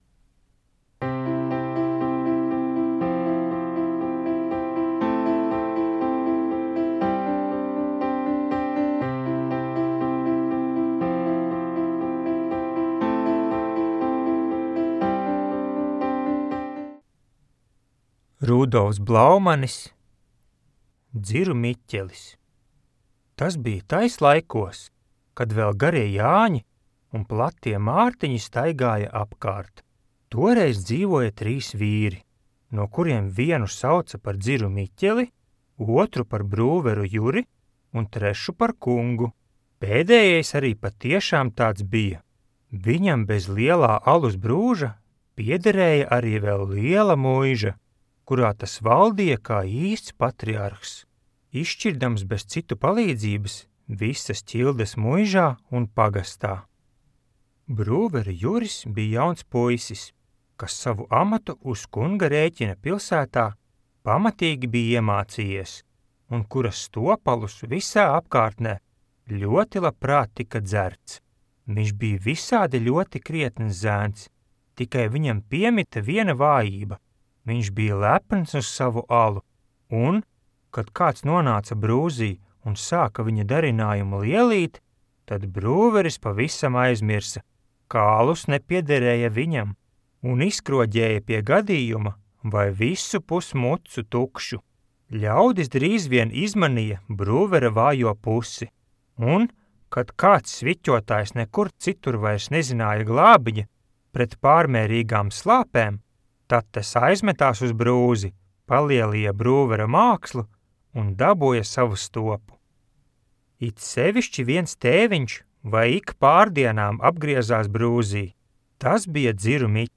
Mājaslapa piedāvā iepazīt latviešu literatūras klasiķu nozīmīgākos daiļdarbus audioierakstā.